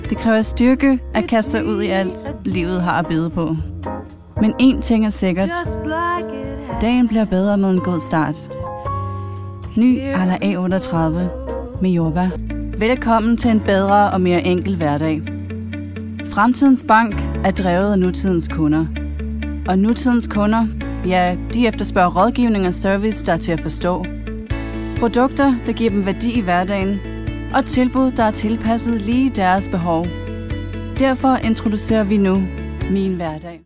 Danish, Scandinavian, Female, Home Studio, 20s-40s